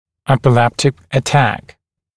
[ˌepɪ’leptɪk ə’tæk][ˌэпи’лэптик э’тэк]эпилептический припадок